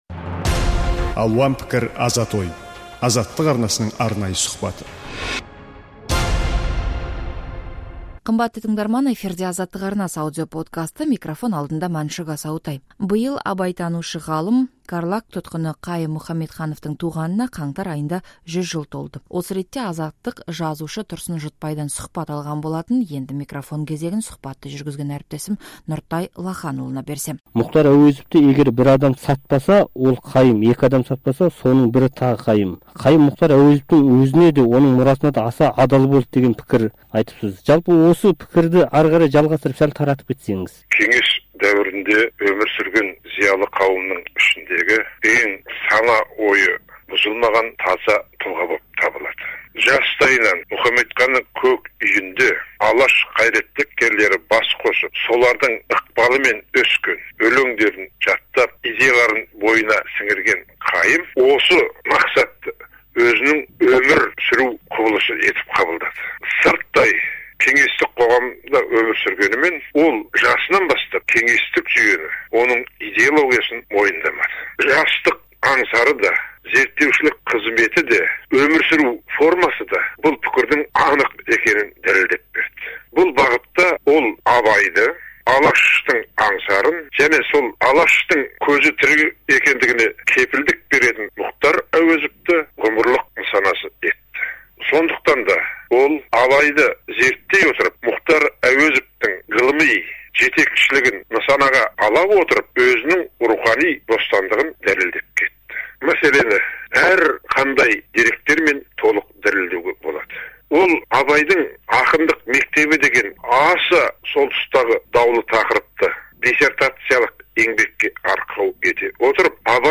Ғалым Қайым Мұхамедхановтың өмірі мен еңбектері жайлы жазушы Тұрсын Жұртбай Азаттыққа сұхбат берді.